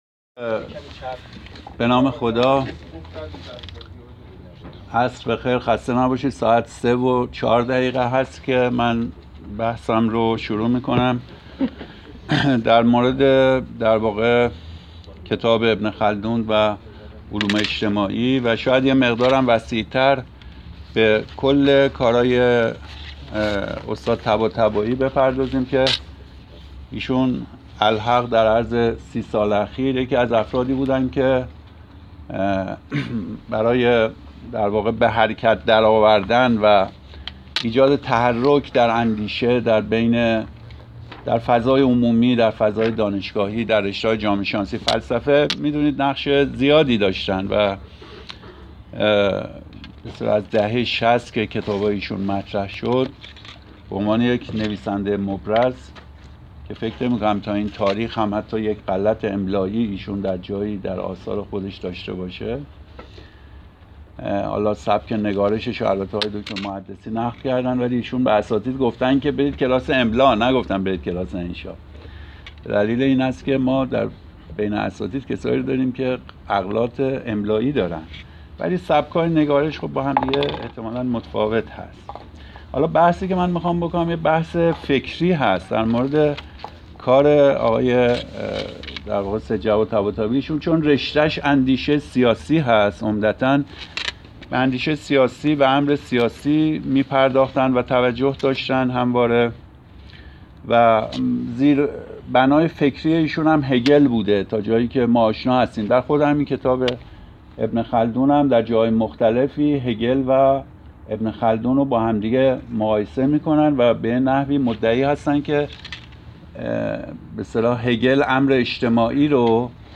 فایل صوتی این سخنرانی را در بالا می توانید بشنوید.